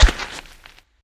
large_step1.ogg